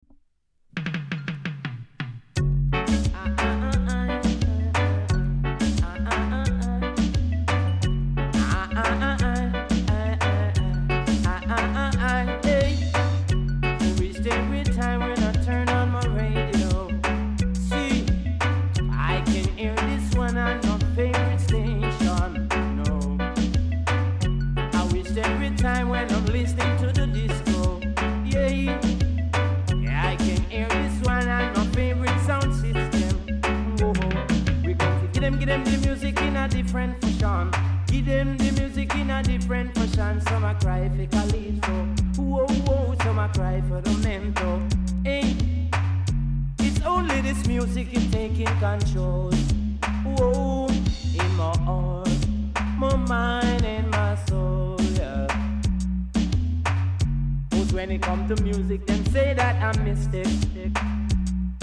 ※多少小さなノイズはありますが概ね良好です。